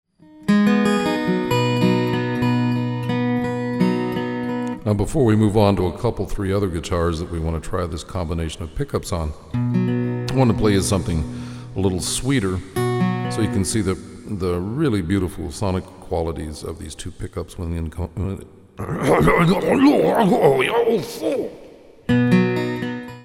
However, at times I have the presence of mind to defer to my rather copious arsenal of Japanese water skiing expletives.